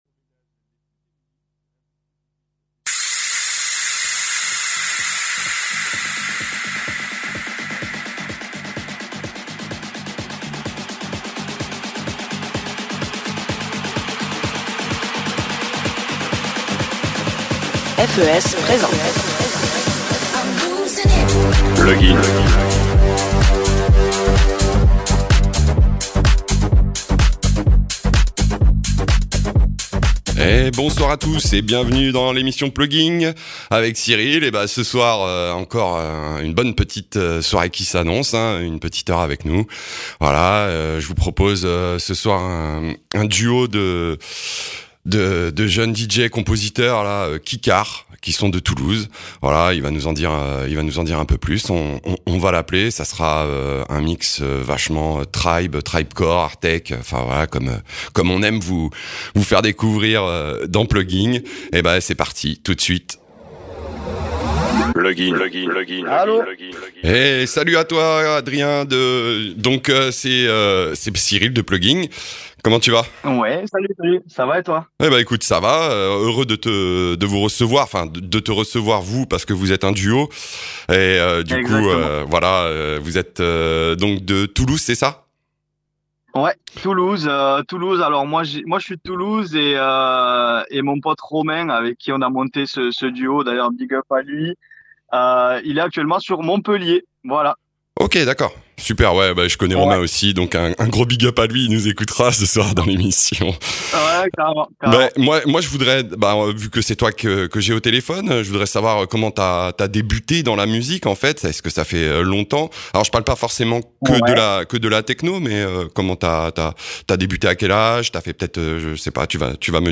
Ce soir ils nous amènent dans leur monde Hardtek-TribeCore